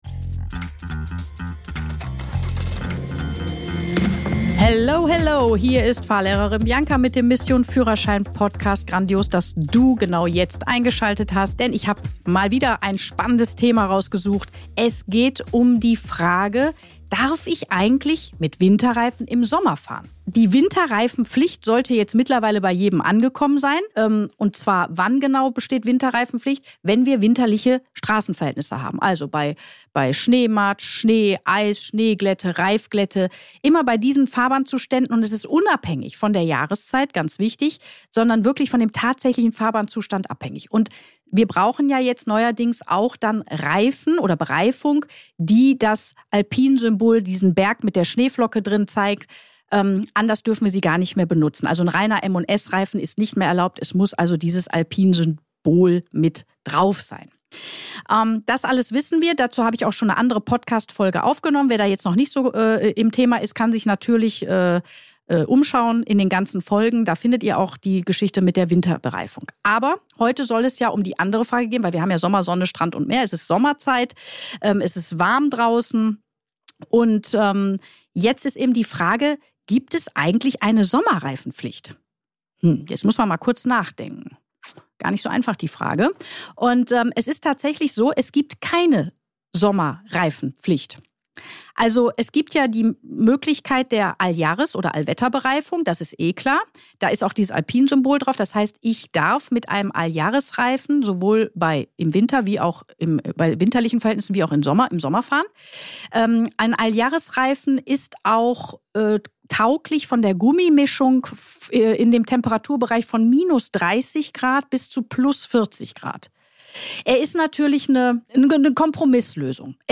Fahrlehrerin deckt auf: Gibt es eine Sommerreifenpflicht?! 🛞 ~ Mission Führerschein - Der Fahrschul-Podcast Podcast